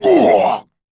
Jin-Vox_Damage1_kr.wav